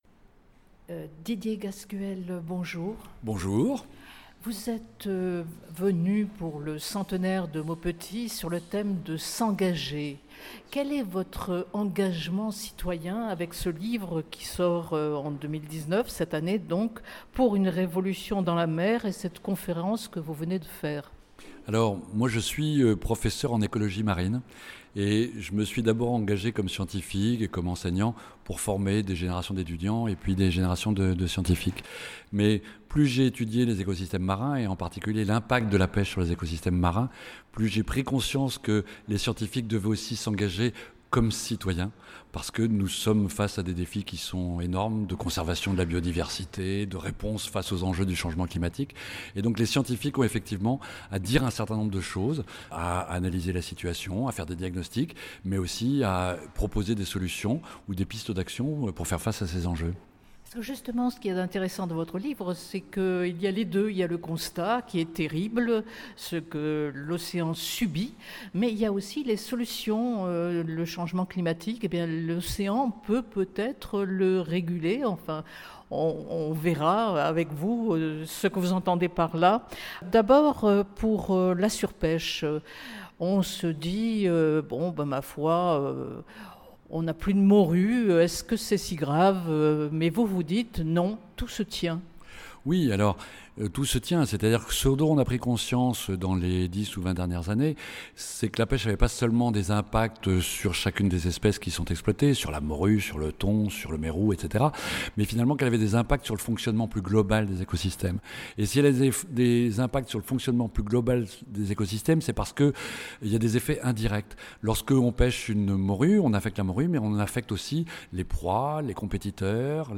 Les réjouissances qui ont commencé dès le mois de janvier, se poursuivent cet automne avec trois samedis, trois rencontres sur la thématique de l’engagement citoyen.